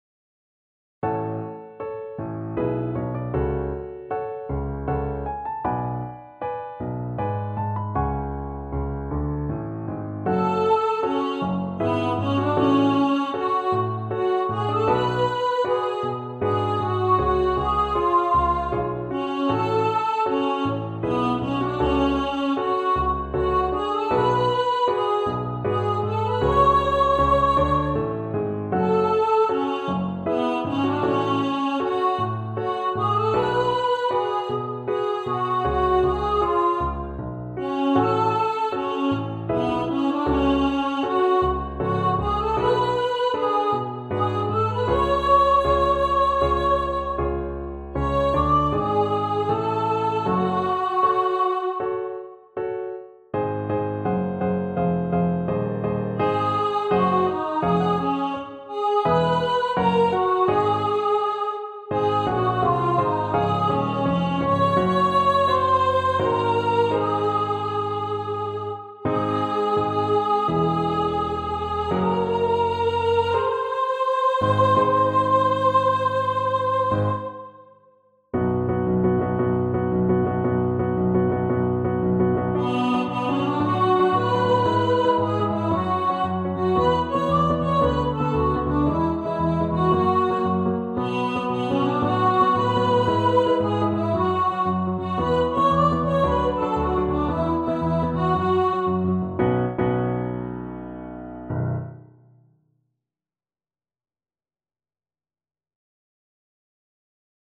Jordens-Soejler-Ensemble-Audition-suite-ALT-BAS.mp3